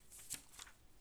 Book2.wav